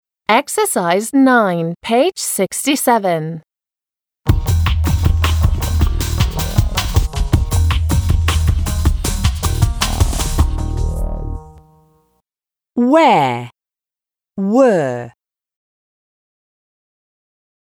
• where [уеэ:] − где
• were [уё:] − были